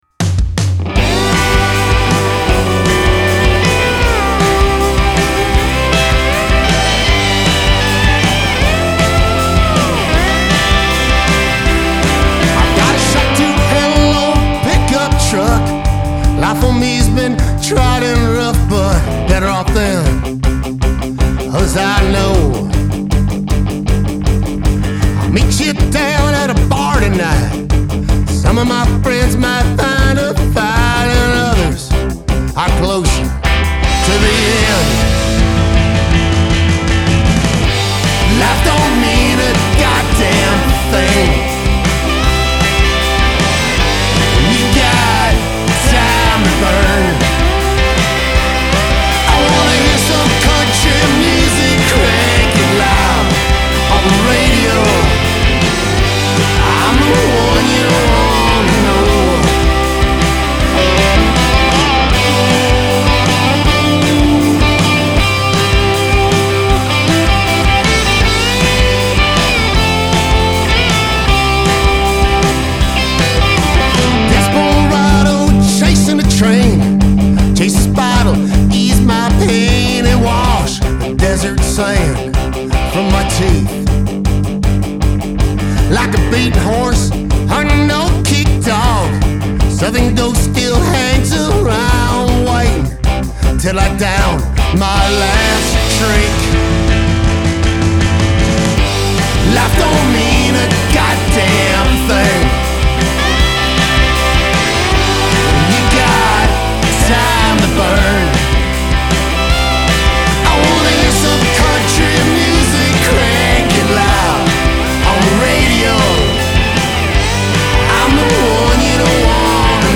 Bass
Drums
Pedal Steel
It added a Replacements feel to the honky tonk vibe.